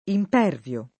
vai all'elenco alfabetico delle voci ingrandisci il carattere 100% rimpicciolisci il carattere stampa invia tramite posta elettronica codividi su Facebook impervio [ imp $ rv L o ] agg.; pl. m. ‑vi (raro, alla lat., -vii )